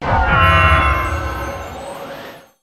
baxcalibur_ambient.ogg